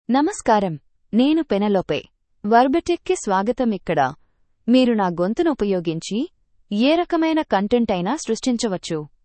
FemaleTelugu (India)
Penelope — Female Telugu AI voice
Penelope is a female AI voice for Telugu (India).
Voice sample
Penelope delivers clear pronunciation with authentic India Telugu intonation, making your content sound professionally produced.